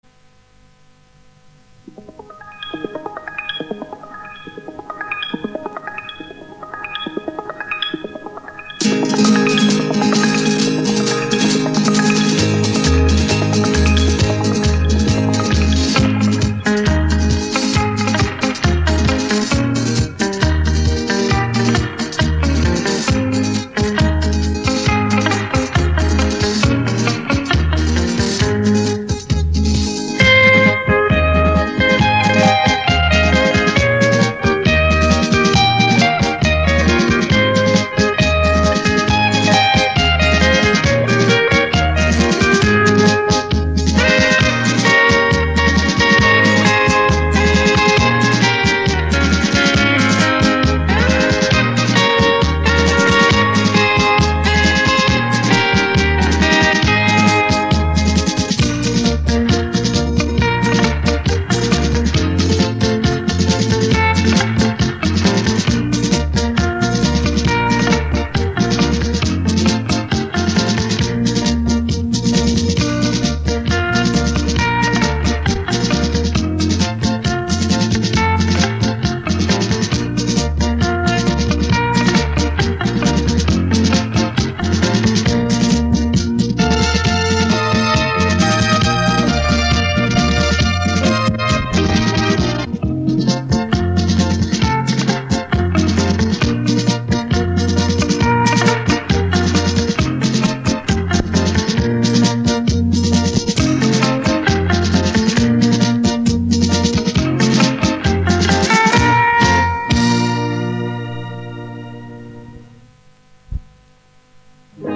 此曲目由磁带转录。双头吉他能发出截然不同的2种声音，犹如木棒敲击声和很柔美的声音，